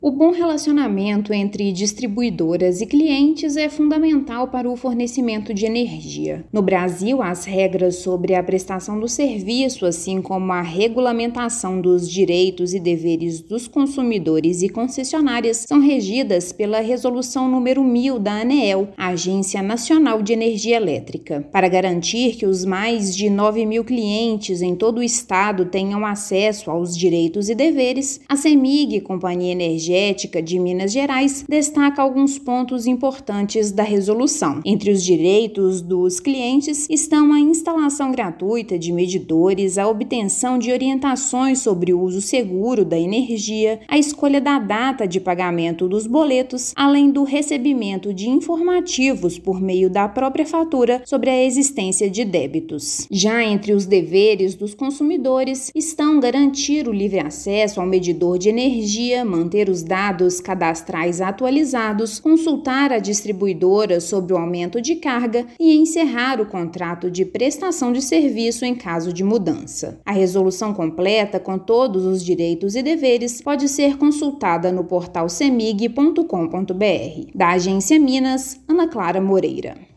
Termos estão em resolução da Aneel que regulamenta o relacionamento entre distribuidoras e consumidores no país. Ouça matéria de rádio.